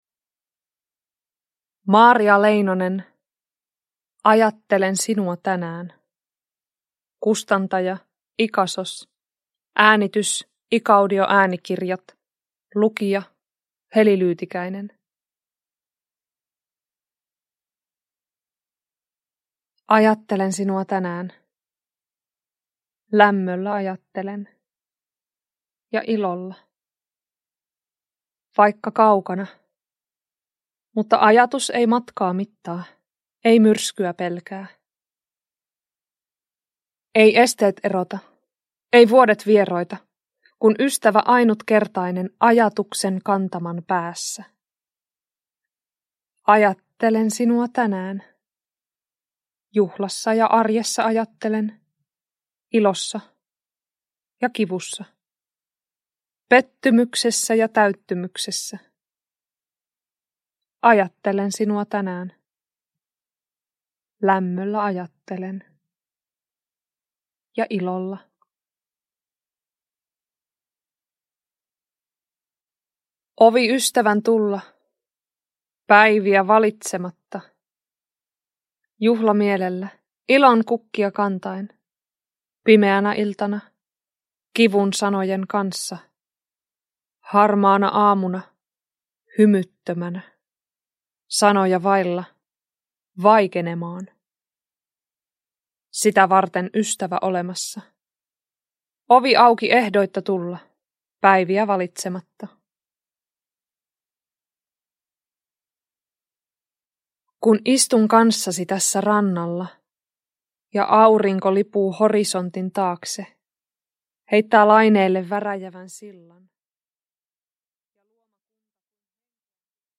Lyrik Njut av en bra bok Romaner